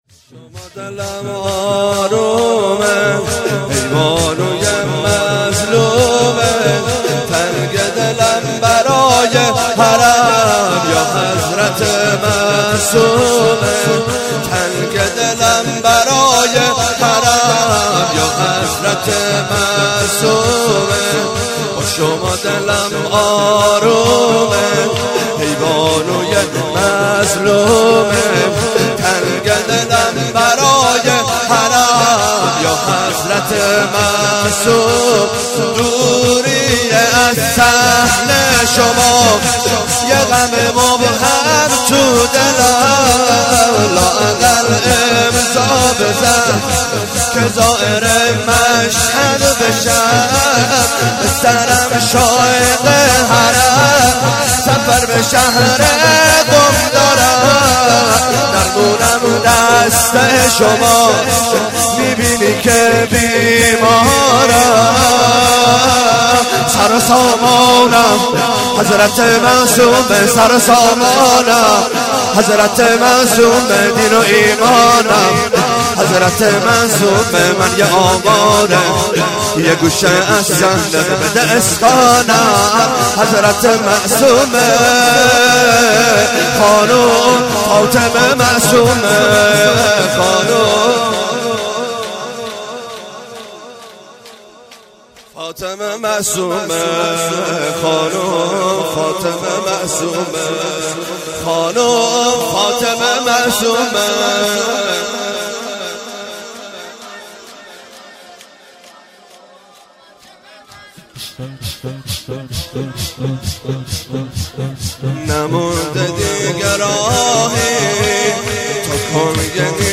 شور - با شما دلم آرومه